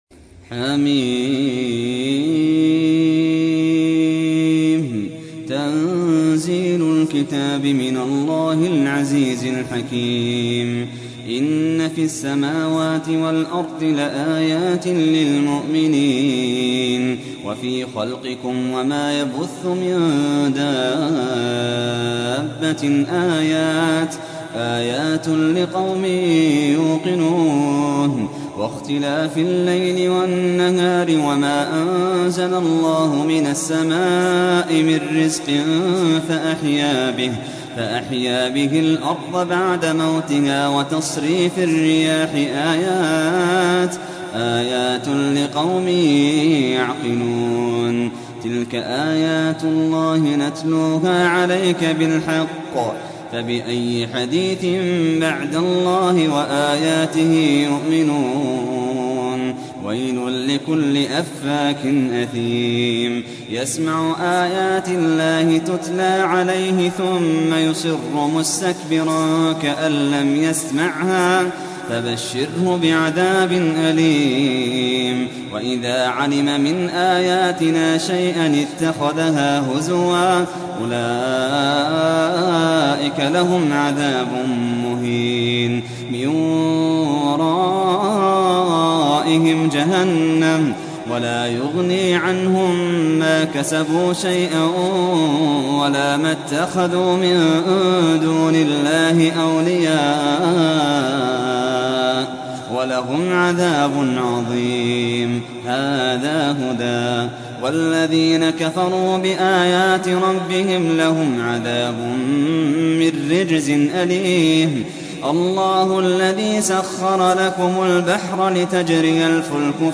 تحميل : 45. سورة الجاثية / القارئ محمد اللحيدان / القرآن الكريم / موقع يا حسين